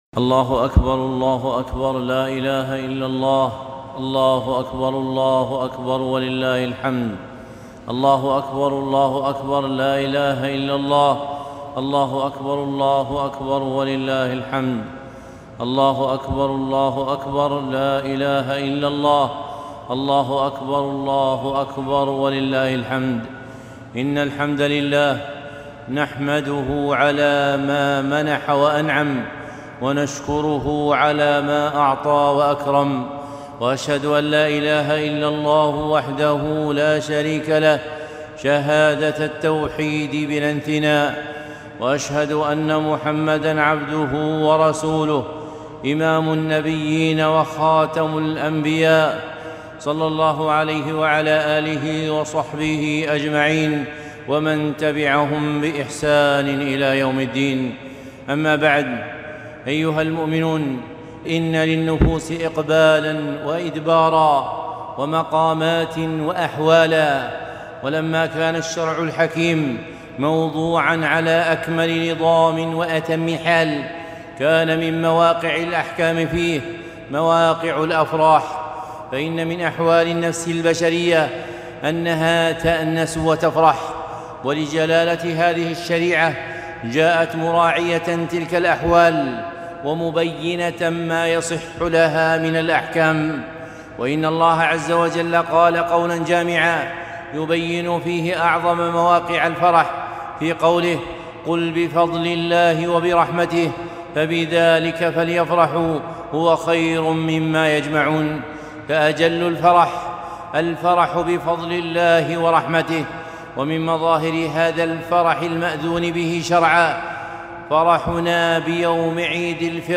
خطبة عيد الفطر ١٤٤٢